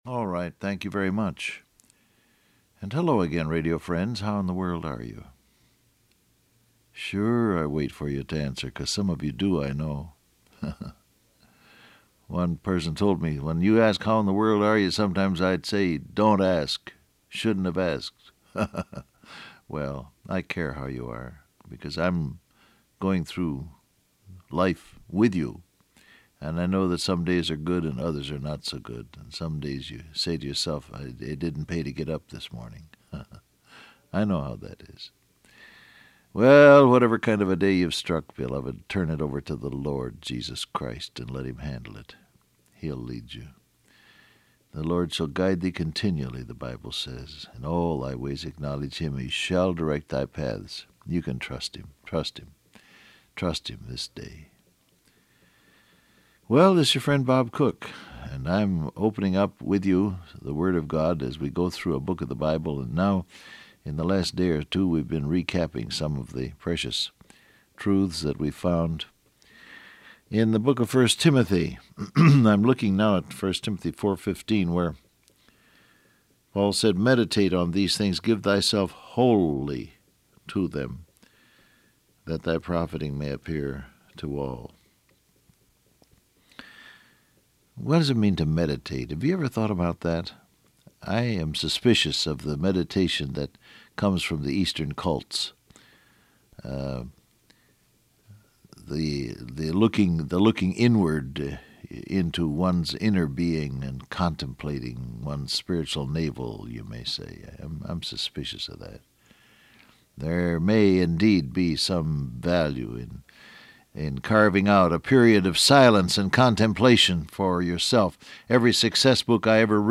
Download Audio Print Broadcast #6787 Scripture: 1 Timothy 4 , 1 Timothy 4:15, Joshua 1:8 Topics: Surrender , Obedience , Meditate Transcript Facebook Twitter WhatsApp Alright, thank you very much.